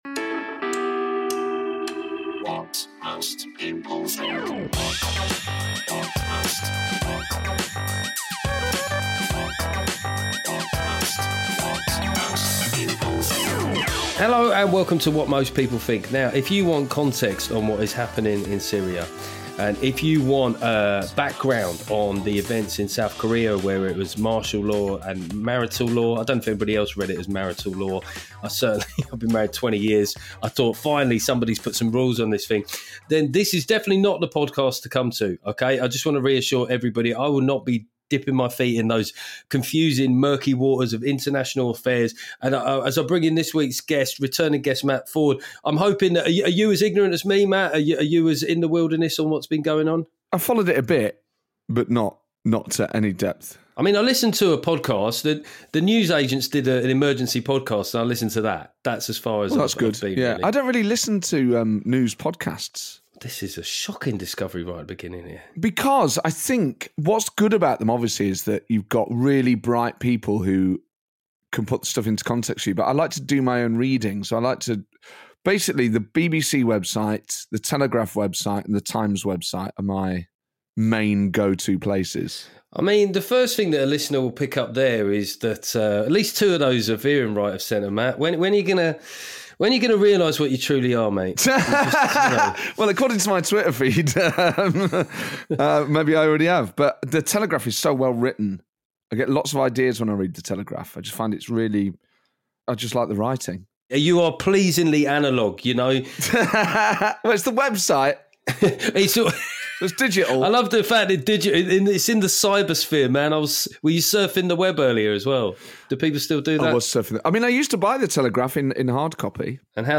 Was delighted to welcome back the brilliant Matt Forde to lock horns over Labour's start to government. Matt also has some wise words about the rise of Reform and why some football fans see their team like the local council. And of course I shamelessly ask him to do some impressions.